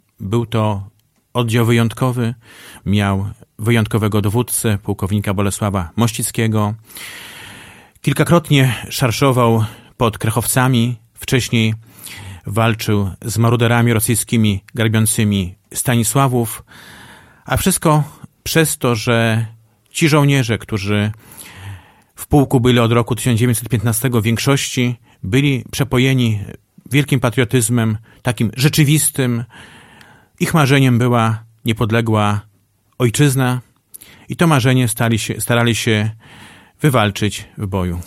O szczegółach mówił we wtorek (18.07) w Radiu 5 Jarosław Szlaszyński, starosta powiatu augustowskiego.